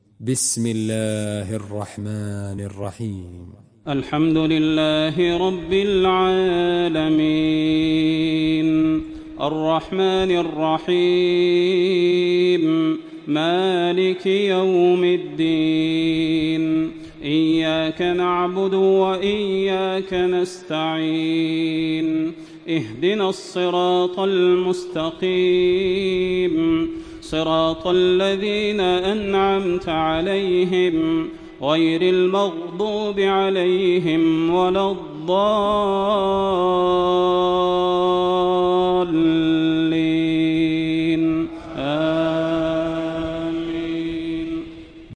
Makkah Taraweeh 1426
Murattal